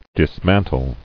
[dis·man·tle]